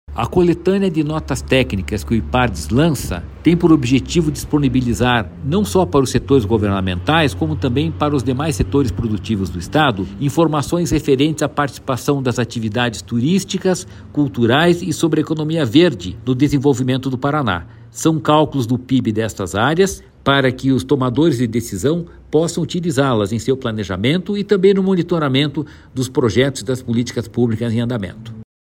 Sonora do diretor-presidente do Ipardes, Jorge Callado, sobre a Coletânea de Notas Técnicas 2023–2024